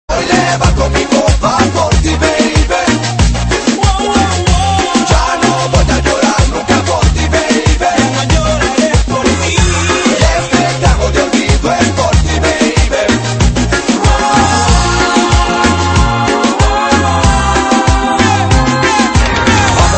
• Latin Ringtones